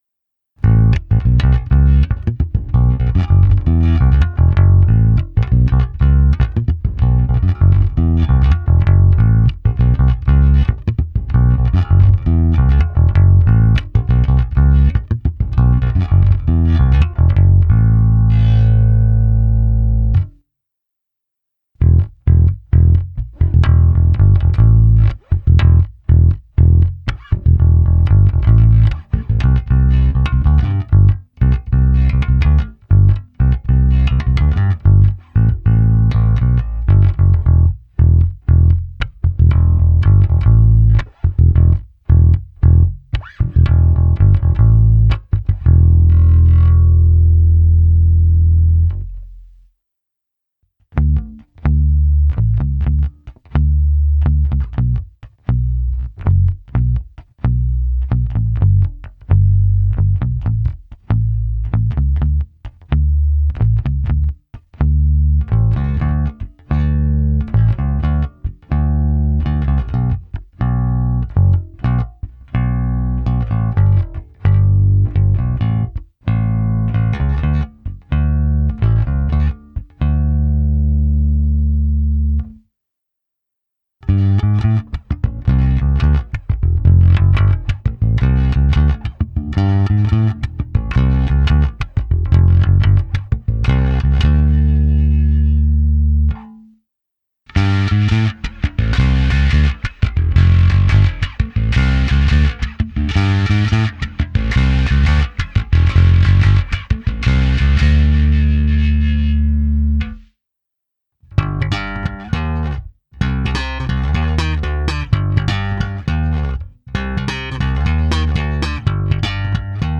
Plné basy tmelící kapelu, výrazné středy, které basu prosadí v mixu, příjemné výšky potřebné pro zkreslení, slap a vyhrávky.
Nahrávka se simulací aparátu, kde bylo použita hra prsty, trsátkem tlumeně a netlumeně, pak ukázka se zkreslením, a nakonec hra slapem.
Simulace aparátu RW